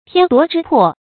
天奪之魄 注音： ㄊㄧㄢ ㄉㄨㄛˊ ㄓㄧ ㄆㄛˋ 讀音讀法： 意思解釋： 魄：魂魄。天奪去了他的魂魄。比喻人離死不遠。